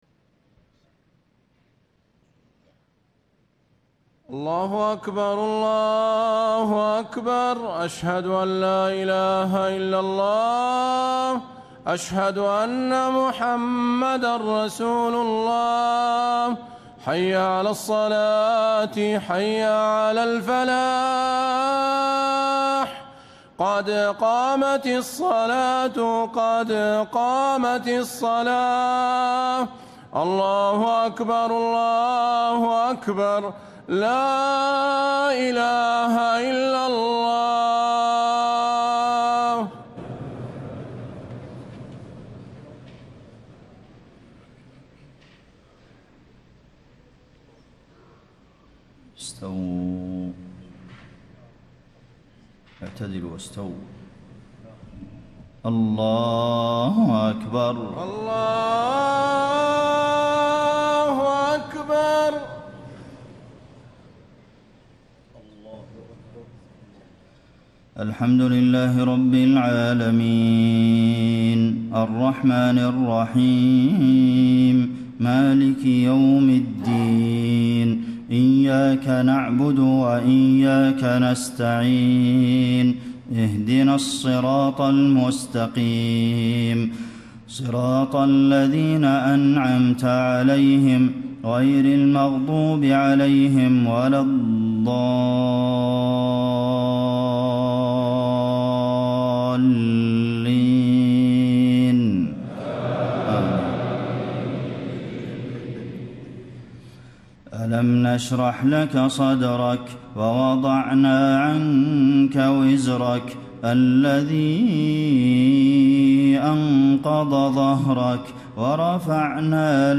صلاة الجمعة 20 رمضان 1435 سورة الشرح و قريش > 1435 🕌 > الفروض - تلاوات الحرمين